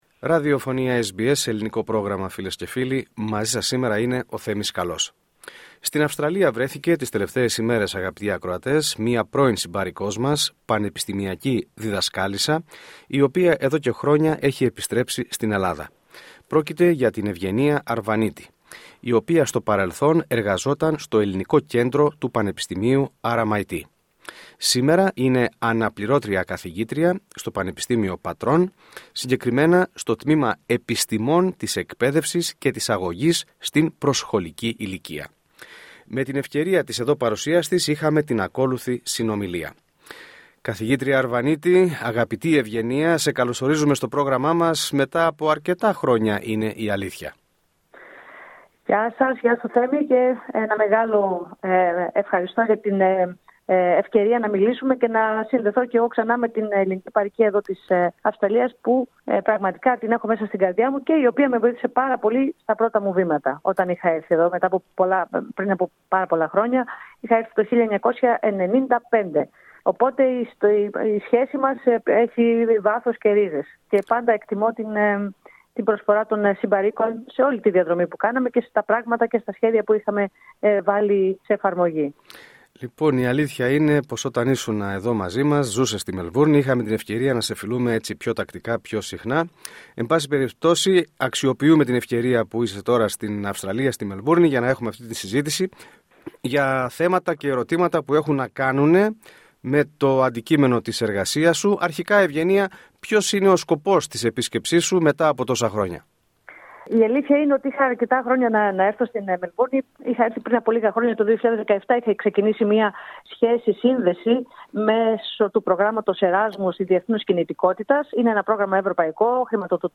Με αφορμή των τωρινή της επίσκεψη στους Αντίποδες παραχώρησε συνέντευξη στο Ελληνικό Πρόγραμμα (SBS Greek) κατά την οποία αναφέρθηκε λεπτομερώς στις επαφές που είχε με ομολόγους της σε μελβουρνιώτικα πανεπιστήμια και με διδασκάλους της ελληνικής γλώσσας στην Ελληνικό κοινότητα της πόλης. Επίσης απαντώντας σε σχετικές ερωτήσεις, αναφέρθηκε στην ελληνόγλωσση εκπαίδευση ειδικά στην παροικία μας και ποιες ενέργειες πρέπει να γίνουν, μίλησε για το πώς μπορούν να βοηθηθούν οι εκπαιδευτικοί στο έργο τους και τέλος στάθηκε στο ποια πρέπει να είναι η σχέση της Ελλάδας με την διασπορά για να ενισχυθούν οι προσπάθειες προώθησης της κουλτούρας και της ταυτότητας.